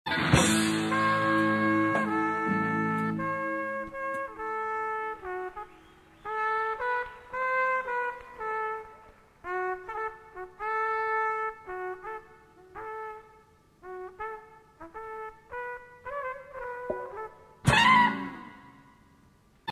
haunting theme
jazz classic